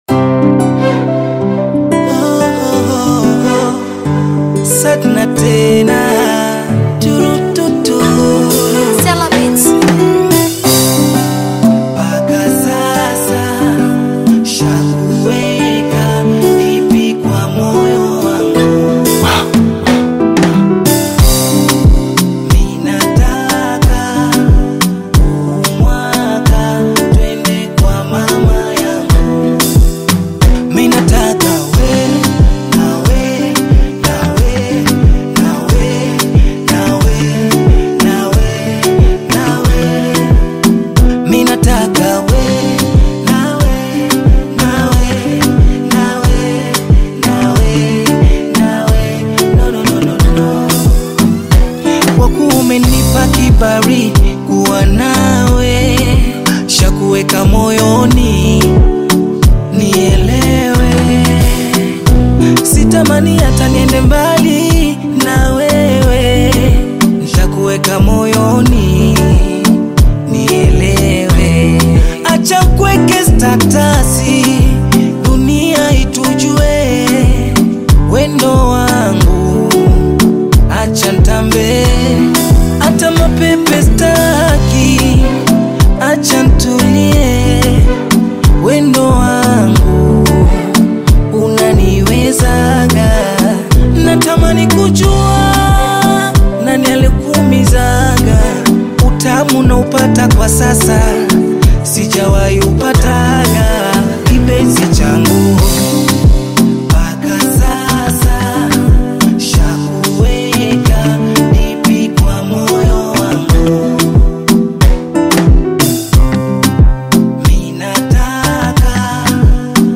Bongo Flava love single